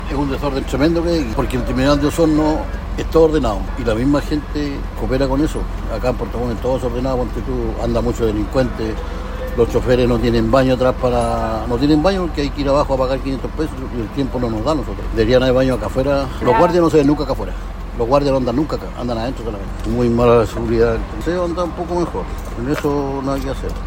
La Radio conversó con diversos trabajadores y pasajeros al interior del rodoviario, quienes comentaban la falta de seguridad en el recinto, como la principal causante de los problemas que se registran.
En esta misma línea, un conductor de buses afirmó que el terminal necesita baños afuera para los trabajadores.
conductor-terminal-de-buses.mp3